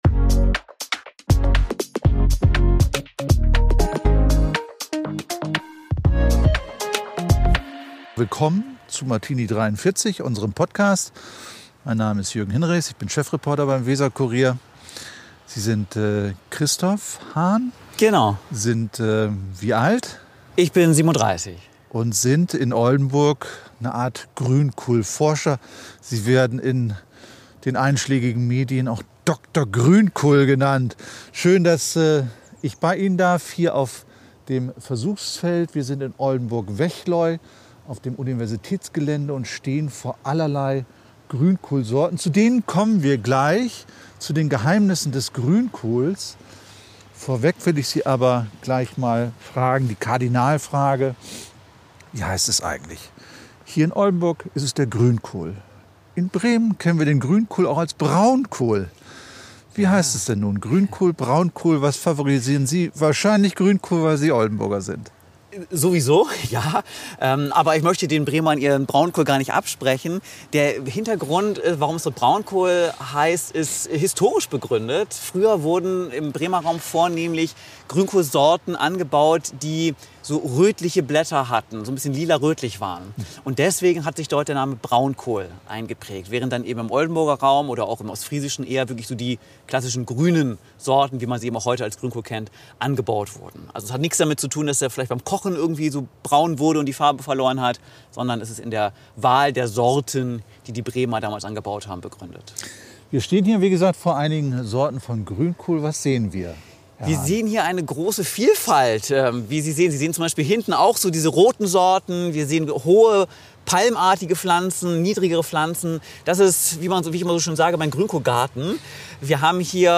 Wie zum Beweis muss der diensthabende Pilot mitten in der Podcast-Aufzeichnung starten
gegen den Lärm der Turbinen fast schon anbrüllen.